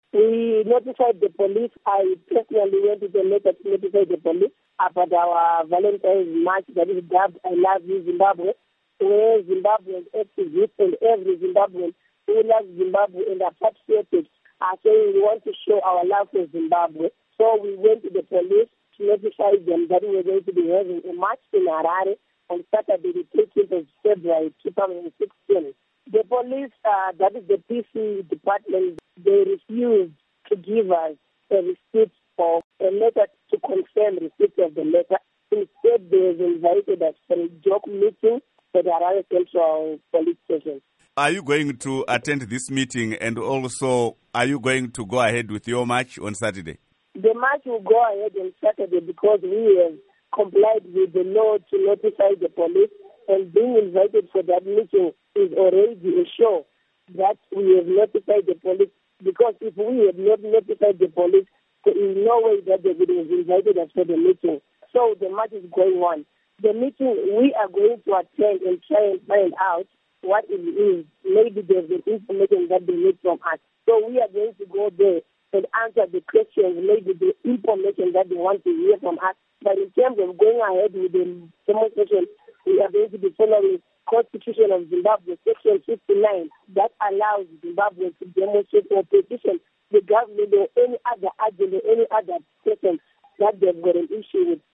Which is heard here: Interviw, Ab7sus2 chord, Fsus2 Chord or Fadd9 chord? Interviw